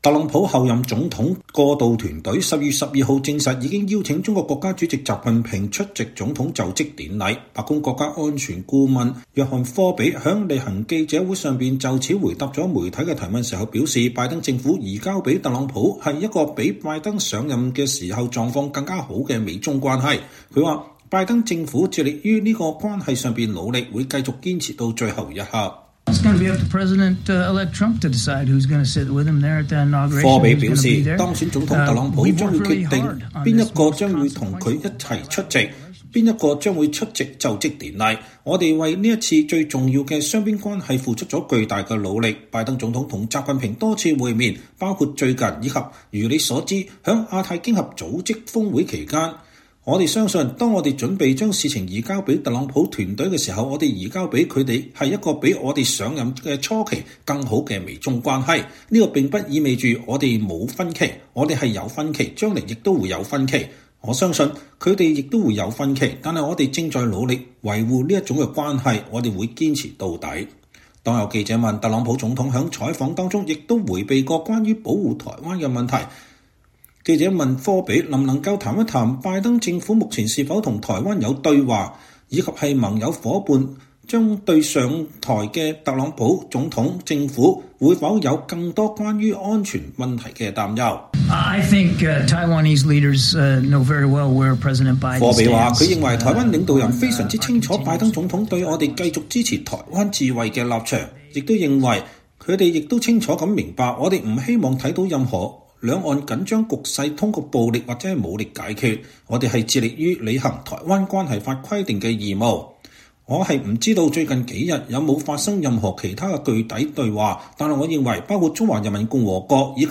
特朗普候任總統過渡團隊12月12日證實已邀請中國國家主席習近平出席總統就職典禮。白宮國家安全溝通顧問約翰·科比在例行記者會上就此回答媒體提問時說，拜登政府移交給特朗普的是一個比拜登上任時狀況更好的美中關係。